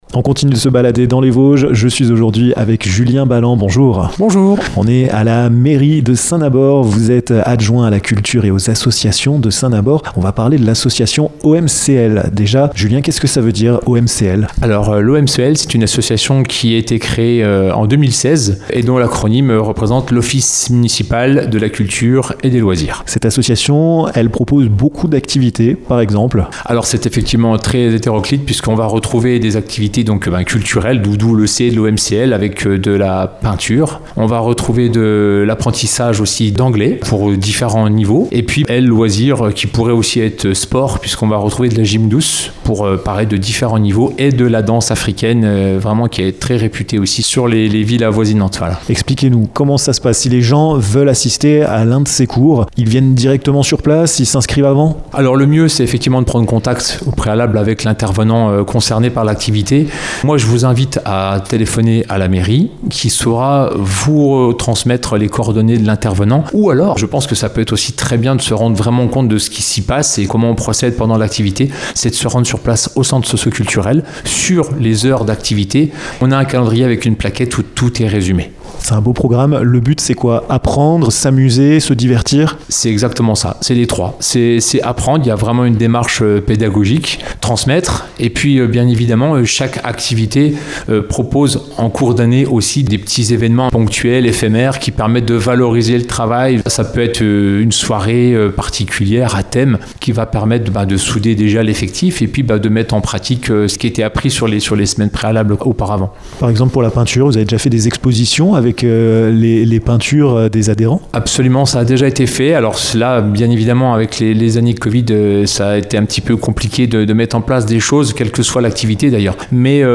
Julien BALLAND, adjoint à la culture et aux associations de la ville de Saint-Nabord, vous présente l'association OMCL (Office Municipal de la Culture et des Loisirs). Vous pouvez assister à différents cours et il y en aura pour tous les goûts !